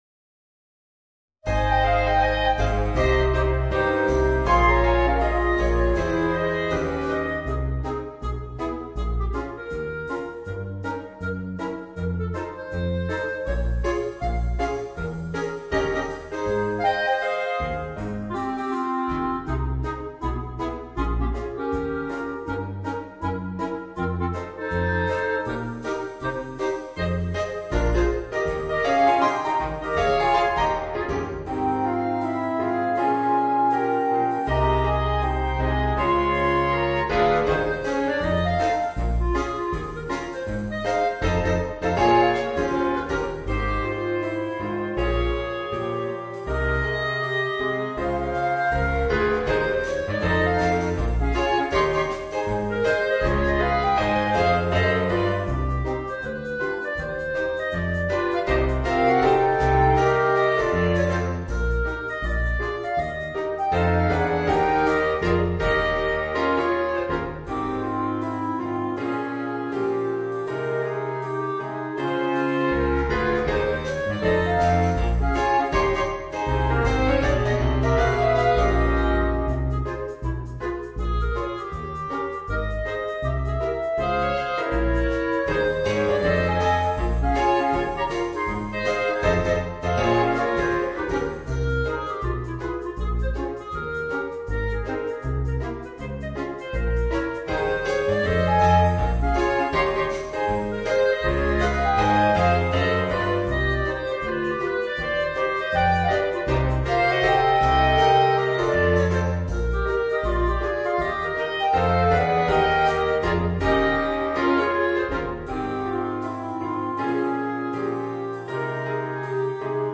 Voicing: 4 Clarinets